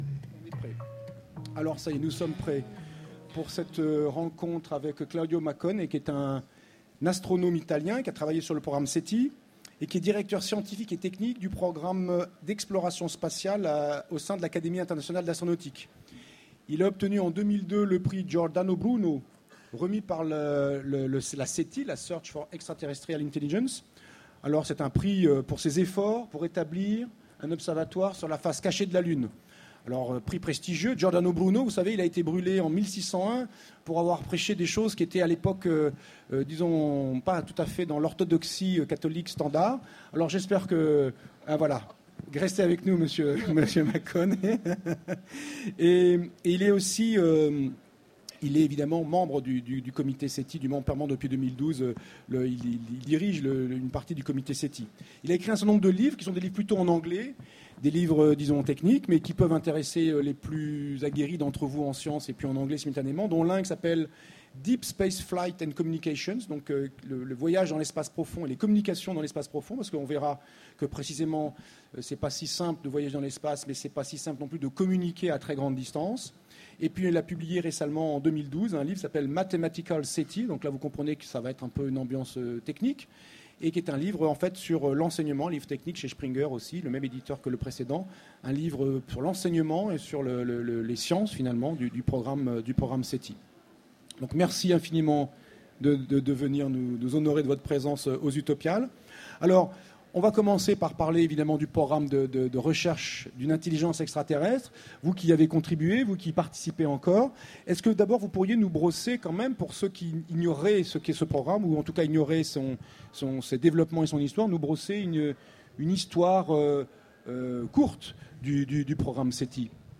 Utopiales 2014
Conférence